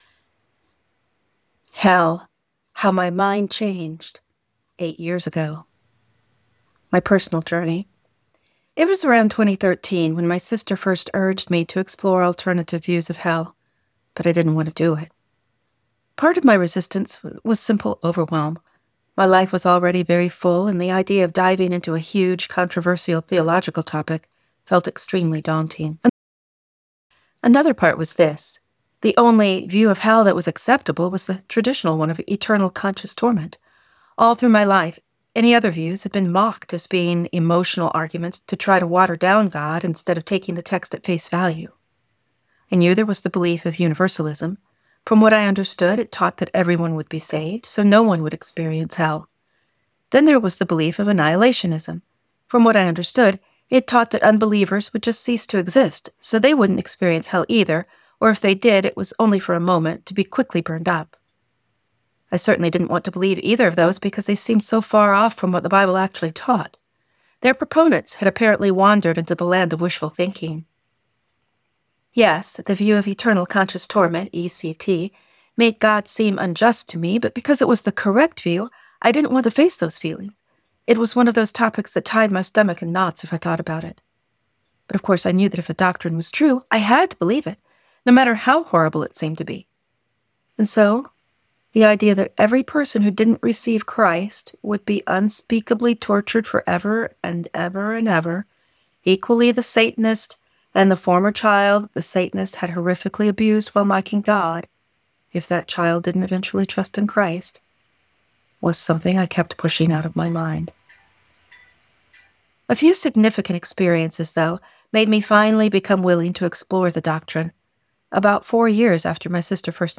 You can listen to me reading this article here: